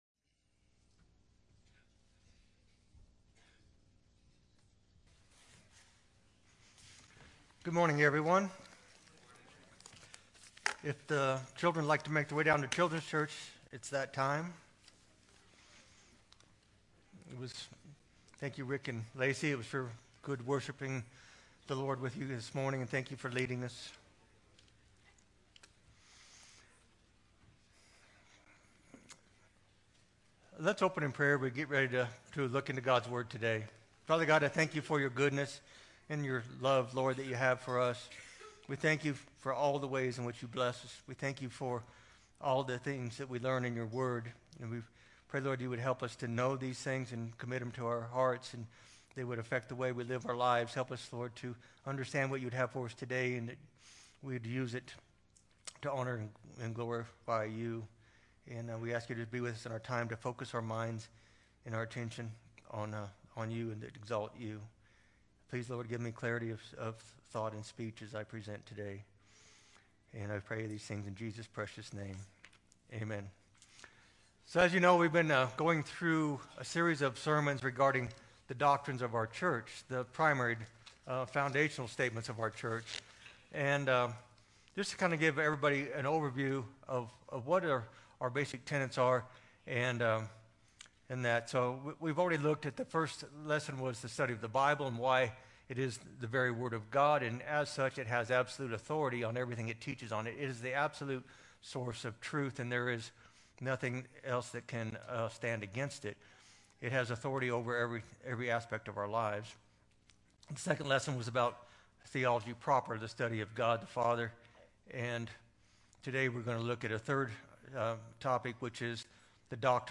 1_16-22-sermon.mp3